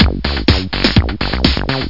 Amiga 8-bit Sampled Voice
1 channel
yazzrythm.mp3